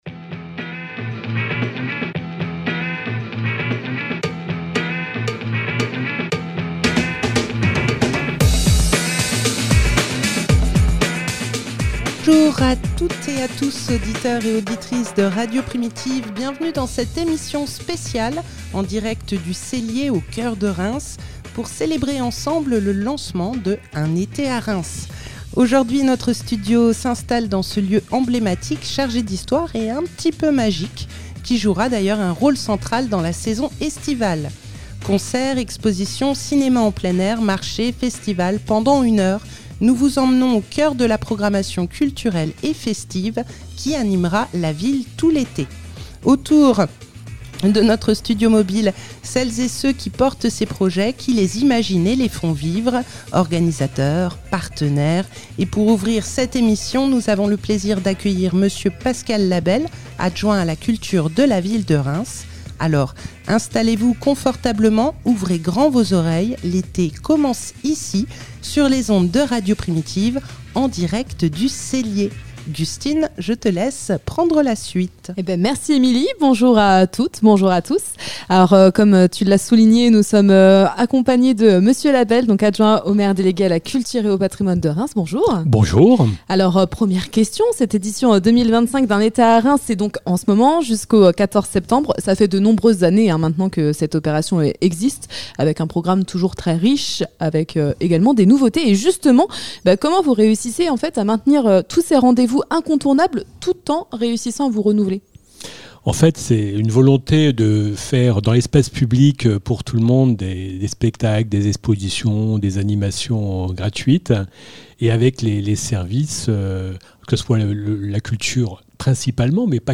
Emission spéciale en direct du Cellier
Le mercredi 11 juin à 18 h, Radio Primitive a posé son studio mobile au Cellier pour célébrer ensemble le lancement d’« Un été à Reims » !